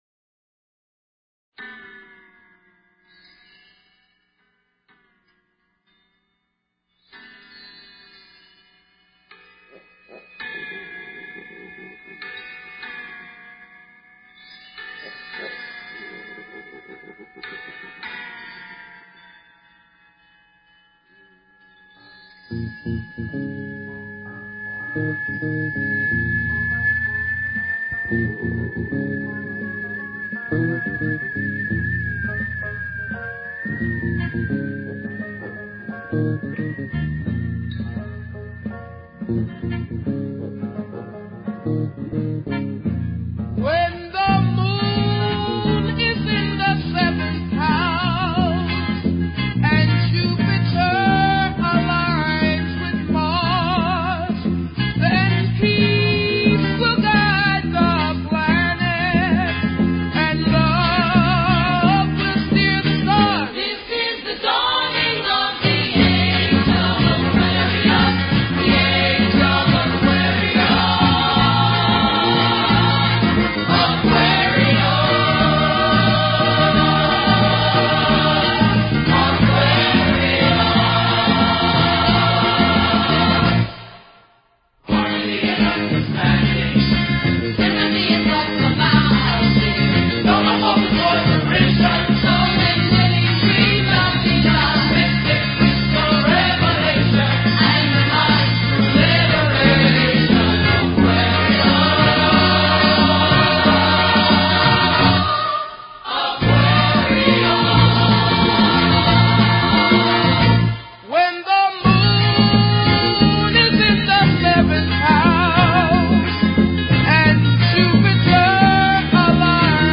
Talk Show Episode, Audio Podcast, Tallkats Psychic 101 and Courtesy of BBS Radio on , show guests , about , categorized as